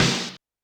Drums_K4(53).wav